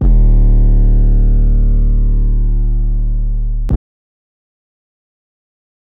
808 (BackToThat).wav